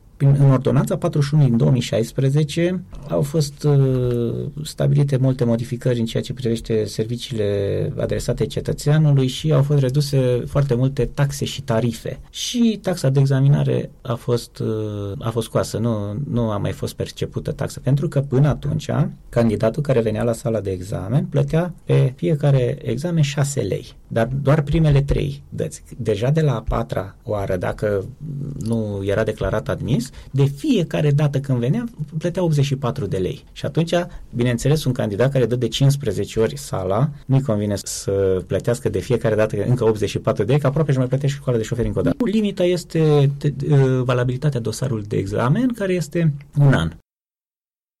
Comisarul șef Mihai Zgârdea, șeful Serviciului Public Comunitar Regim Permise de Conducere și Înmatriculare a Vehiculelor Alba, a mai declarat la Unirea FM că la acest moment tinerii pot susține proba teoretică de cate ori vor, în condițiile în care nu mai există nicio taxă de plătit, așa cum se întâmpla în urmă cu câțiva ani.